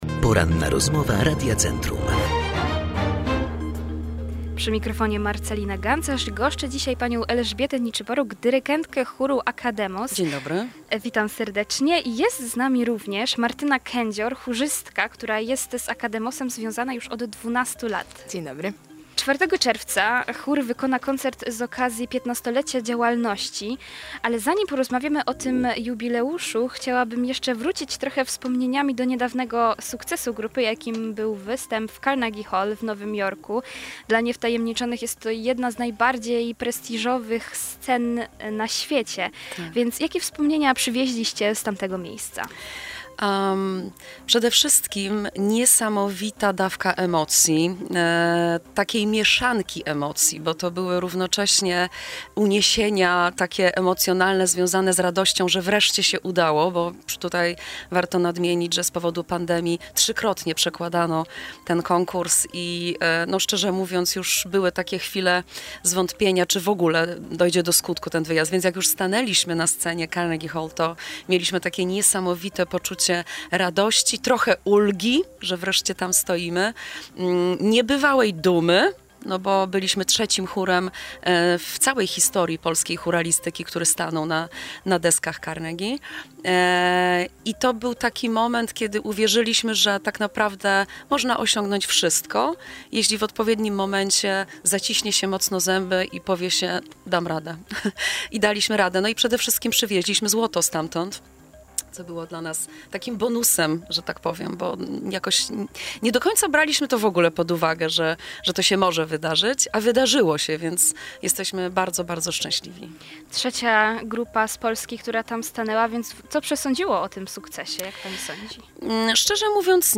15-lecie Akademos cała rozmowa